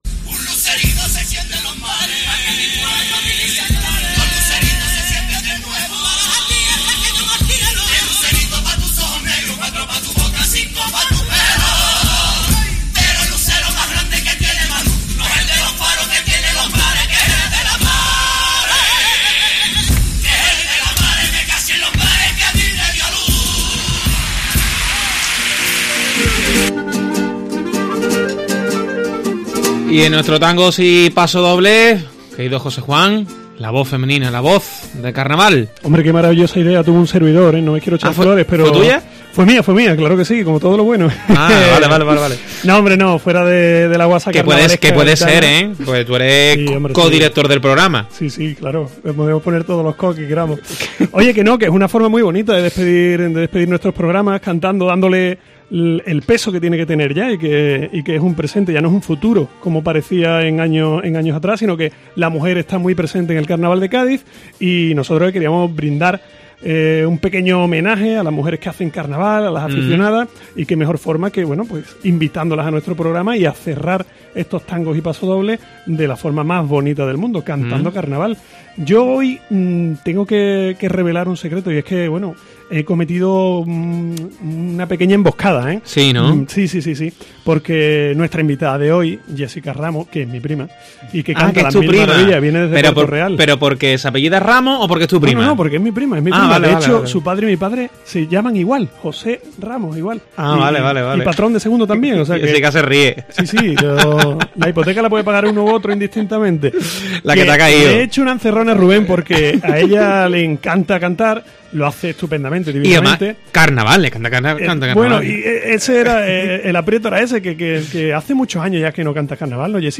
con la guitarra